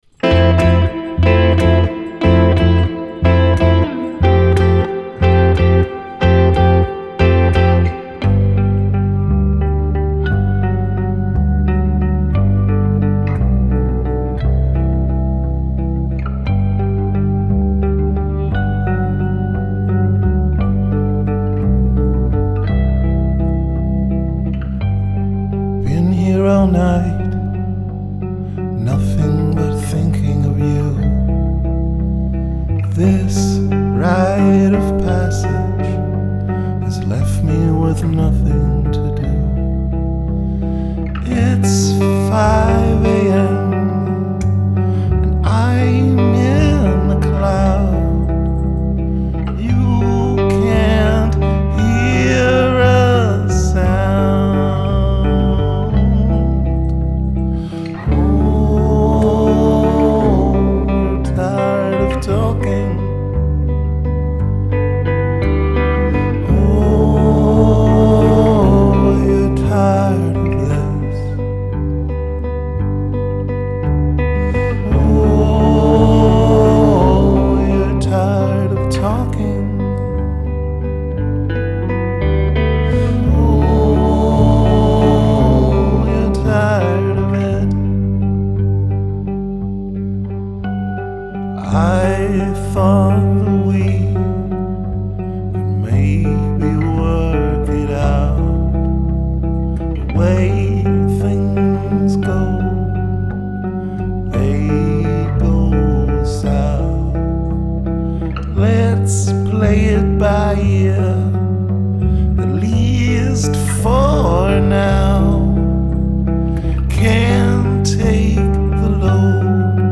Rehearsals 17.3.2012